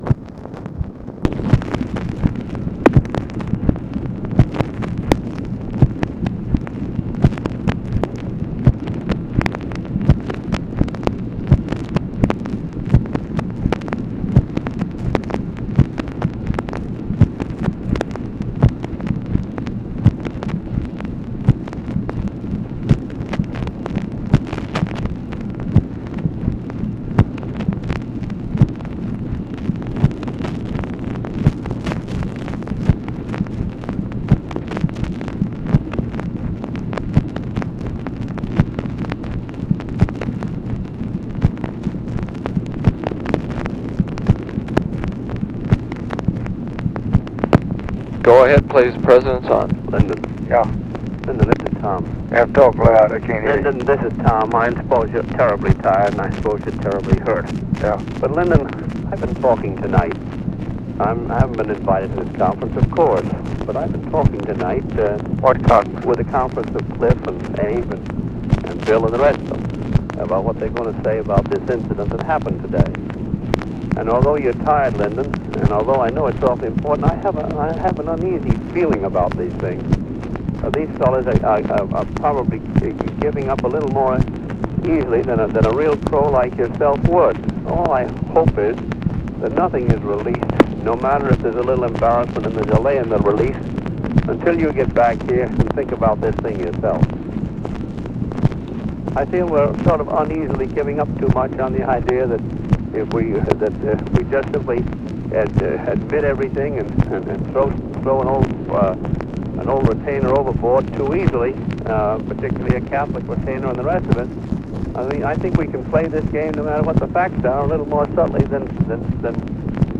Conversation with TOMMY CORCORAN, October 15, 1964
Secret White House Tapes